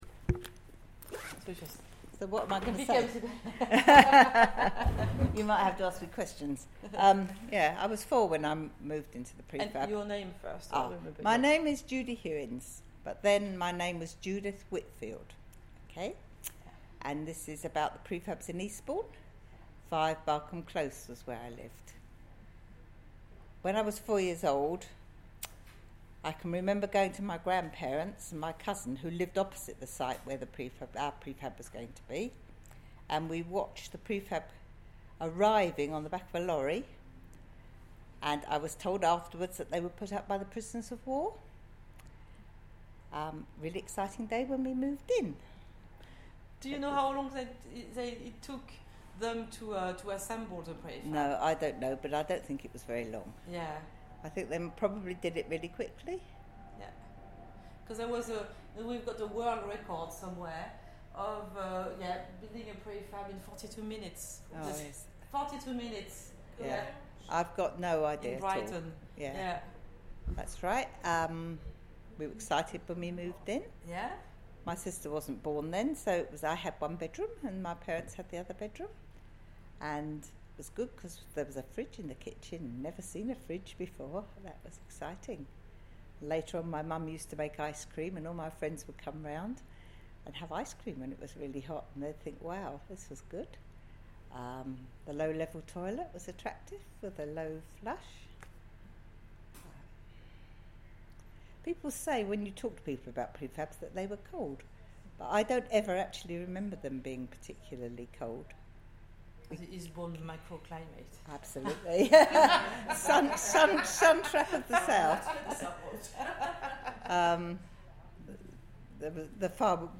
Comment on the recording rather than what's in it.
Moving Prefab Museum Event - Rural Life Centre